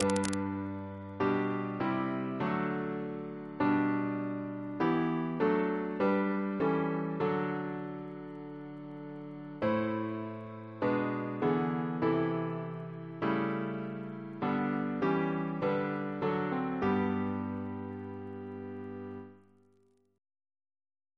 Double chant in G Composer: A. E. Walker Reference psalters: OCB: 77